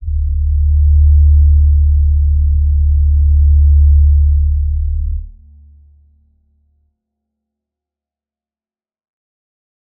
G_Crystal-D2-mf.wav